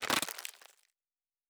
Sci-Fi Sounds
Plastic Foley 09.wav